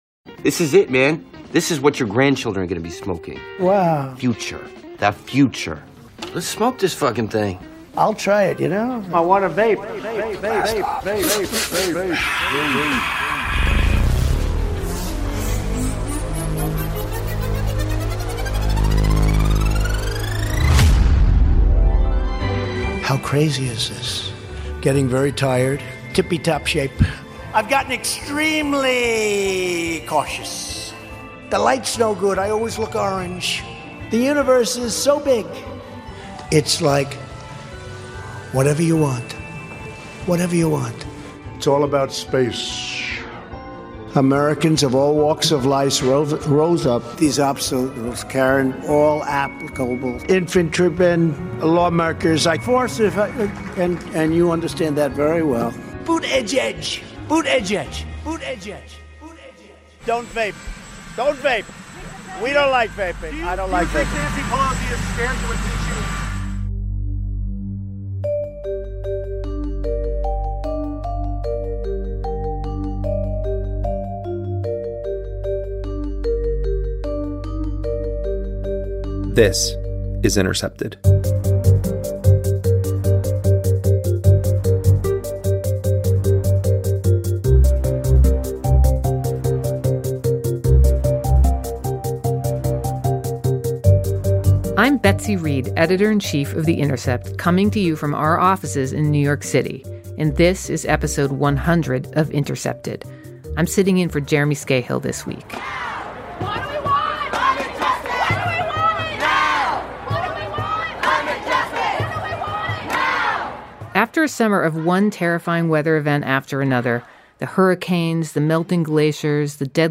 National Security Agency whistleblower Edward Snowden reads an excerpt from his new memoir, “ Permanent Record “, and reflects on his time since revealing the broad scope of NSA surveillance